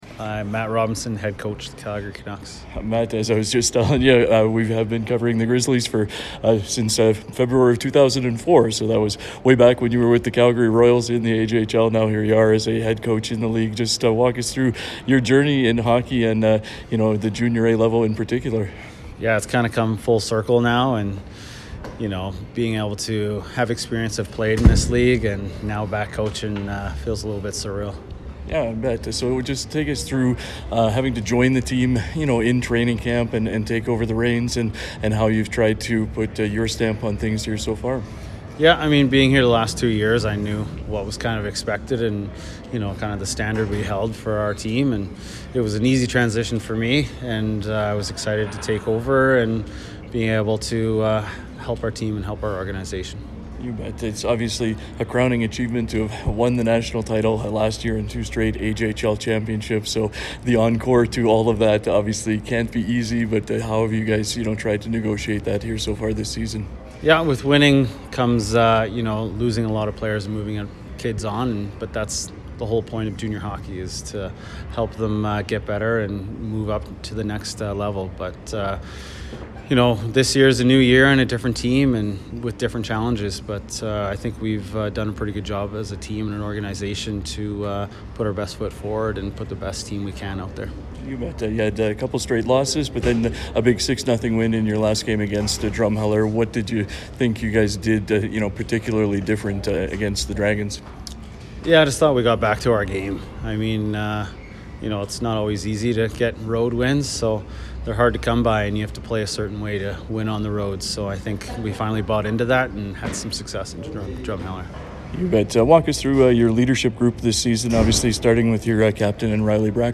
chatted before the game